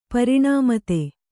♪ pariṇāmate